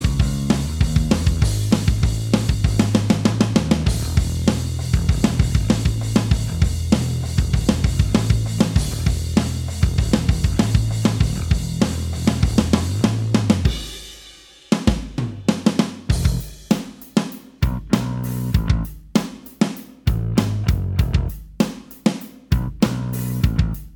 Minus All Guitars Indie / Alternative 3:04 Buy £1.50